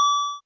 8-bit good pick-up sound effect free sound royalty free Music